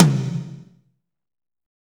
Index of /90_sSampleCDs/Northstar - Drumscapes Roland/DRM_Fast Rock/TOM_F_R Toms x
TOM F RHI0FR.wav